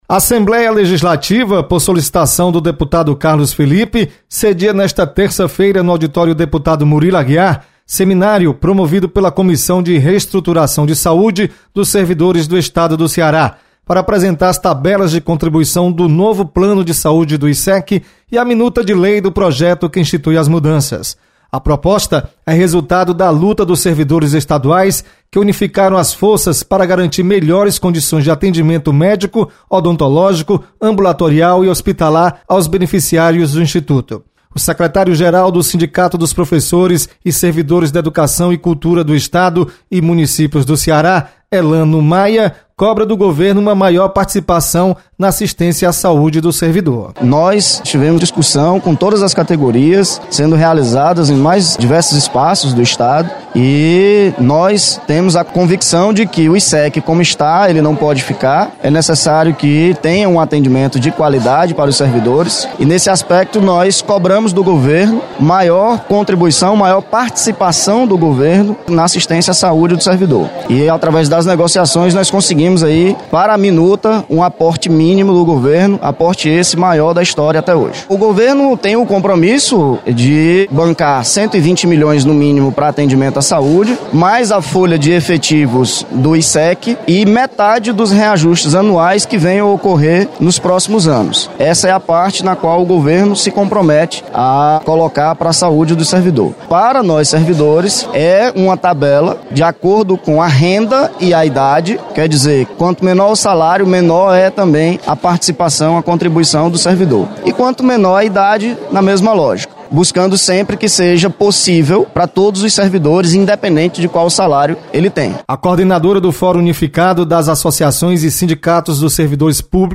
Seminário discute sobre contribuição do plano de saúde do Estado. Repórter